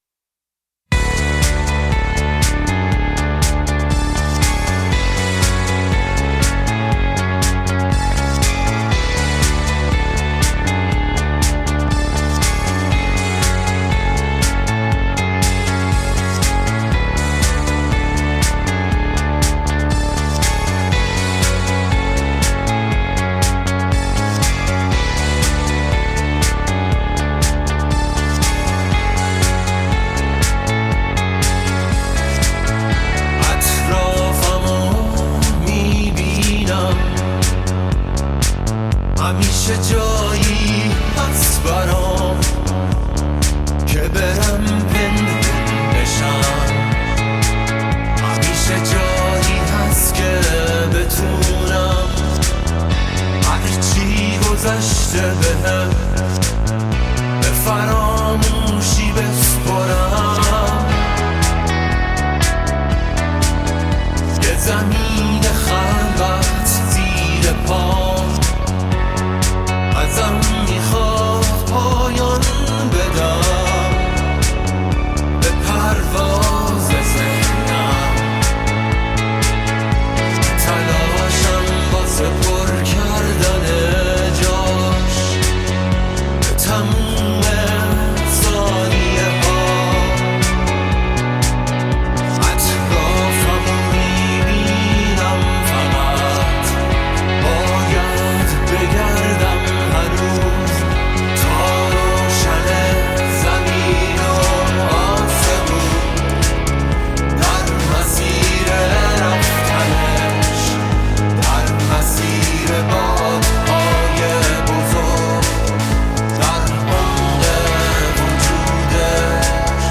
راک ایرانی
موسیقی الکترو راک